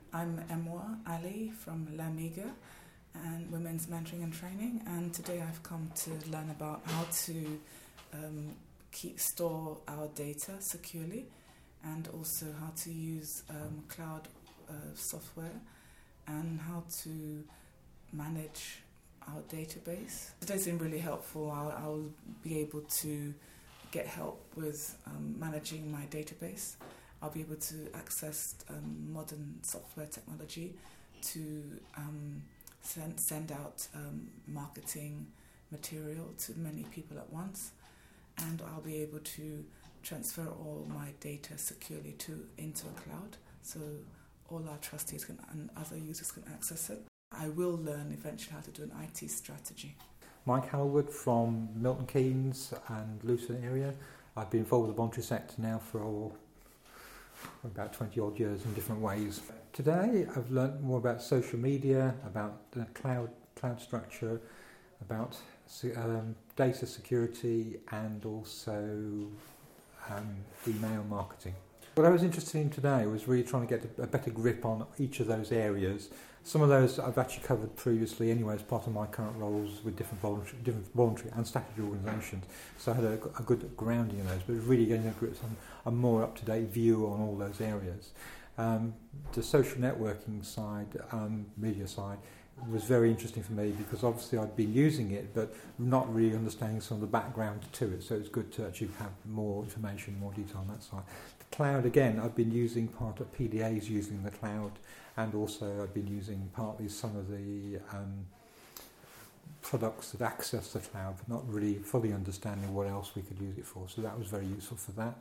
2 clients from the Lasa Technology Surgery day tell us what they got from the day